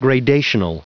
Prononciation du mot gradational en anglais (fichier audio)
Prononciation du mot : gradational